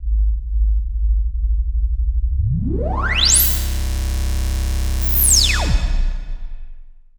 TECHFX  59.wav